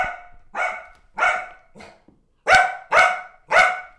dog-dataset
puppy_0031.wav